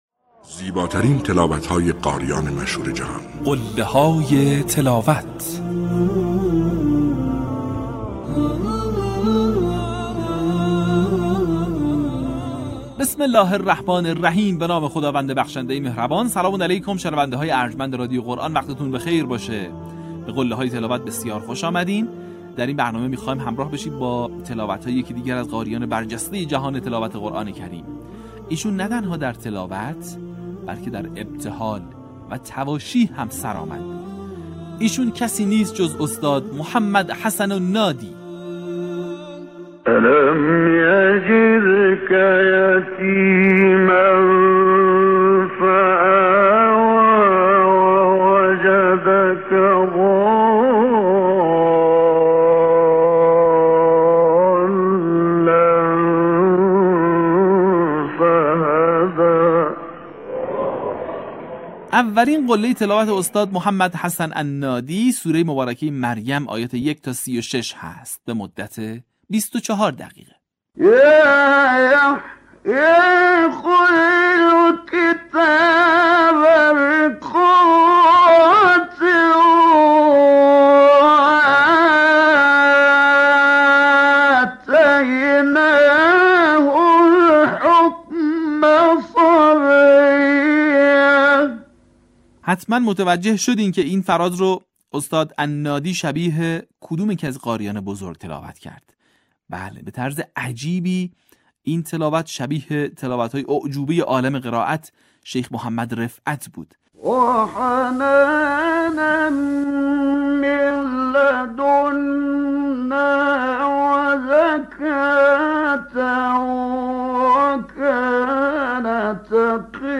در قسمت پنجاه‌وششم فراز‌های شنیدنی از تلاوت‌های به‌یاد ماندنی استاد «محمد حسن النادی» را می‌شنوید.
برچسب ها: محمد حسن نادی ، قله های تلاوت ، فراز تقلیدی ، تلاوت ماندگار